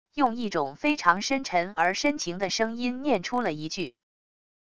用一种非常深沉而深情的声音念出了一句wav音频